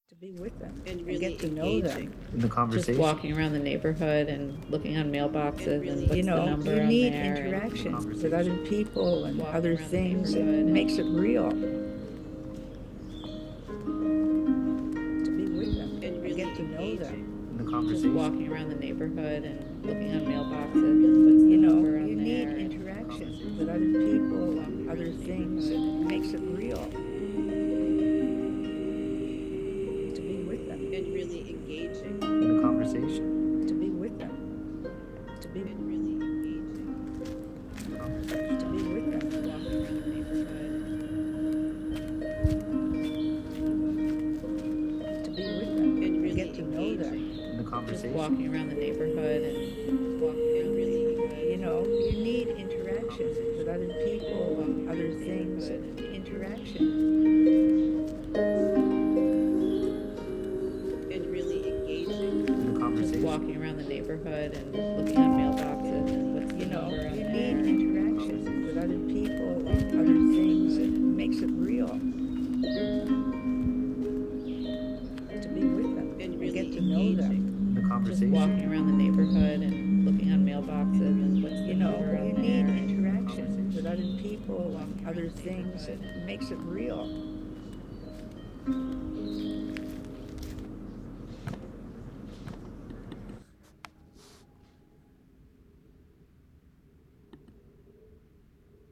This audio collage is about dialogicity (as inspired by Bakhtin), as a way of being, a way of teaching/learning, and a possibility for mathematics education.